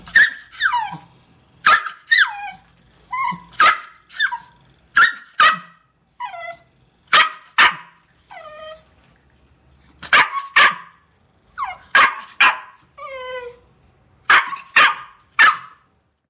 Звуки бульдога
Звук скулящего и пищащего щенка бульдога 6 месяцев